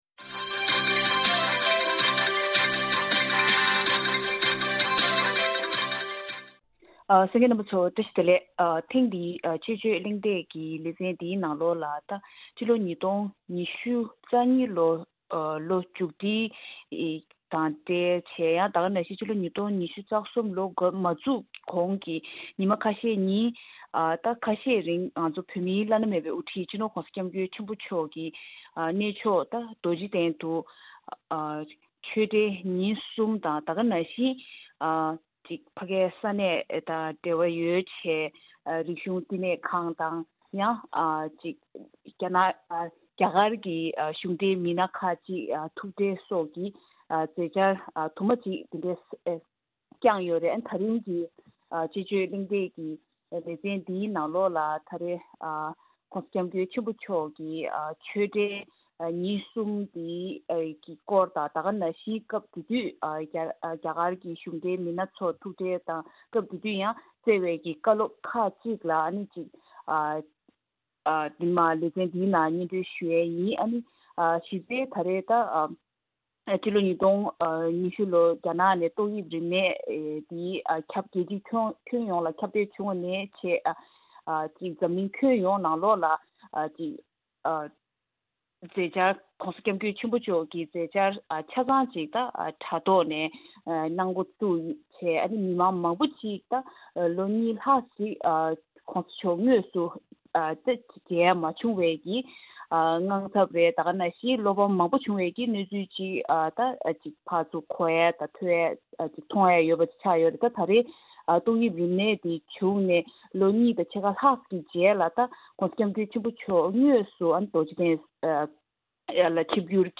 གཤམ་ལ་ད་རེས་ཀྱི་བདུན་རེའི་དཔྱད་བརྗོད་གླེང་སྟེགས་ཀྱི་ལས་རིམ་ནང་།